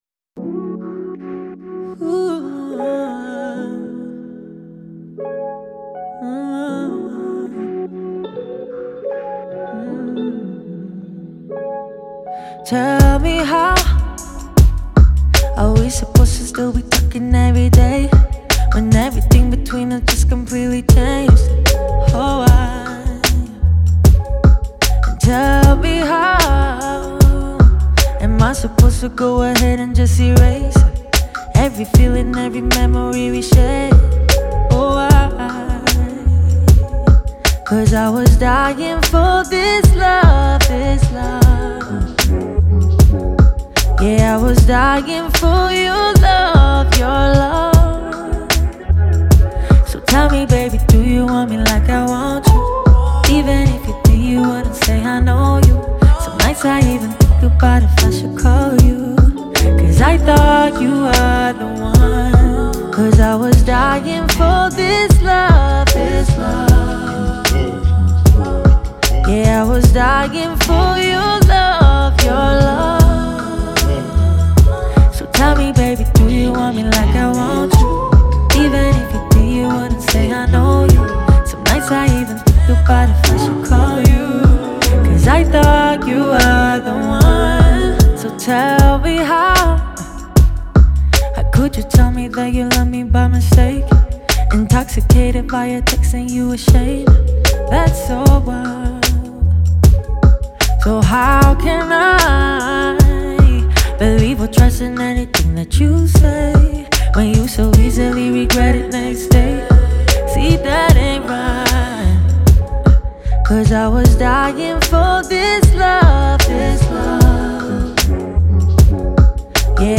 Genre : R&B